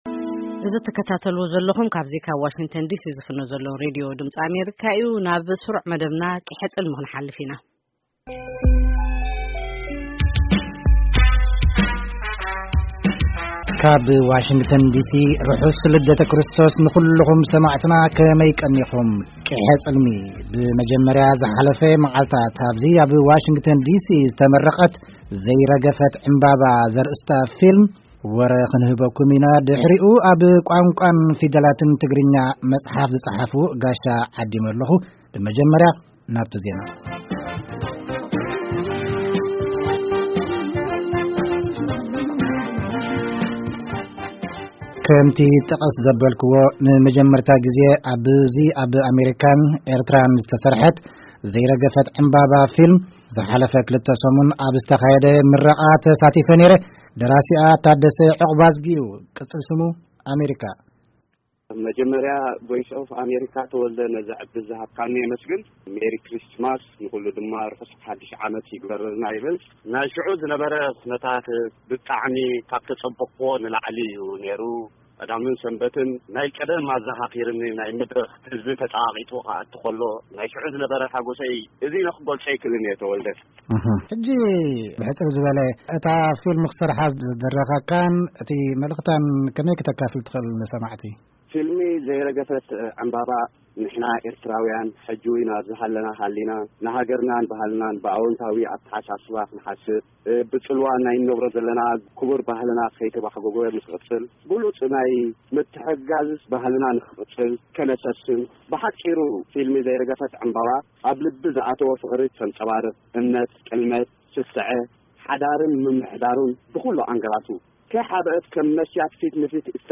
ንኽልቲኦም ኣዘራሪብናዮም ኣለና።